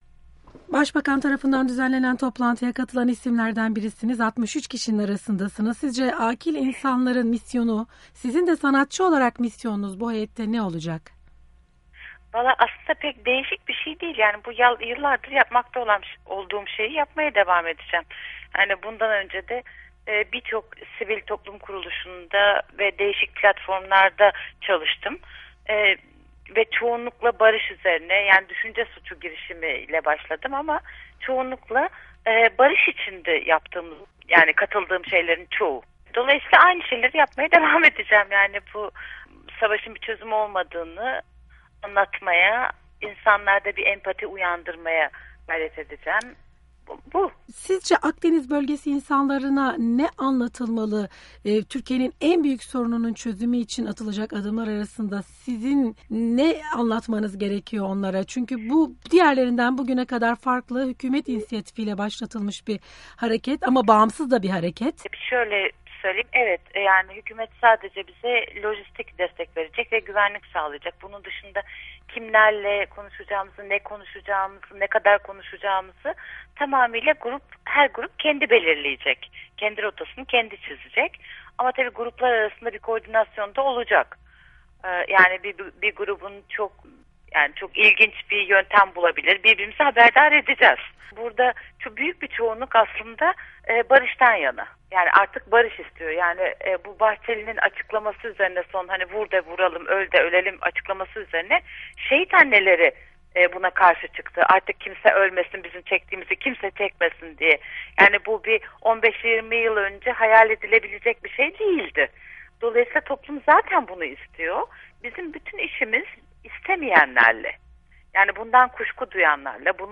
Abdurrahman Dilipak ile Söyleşi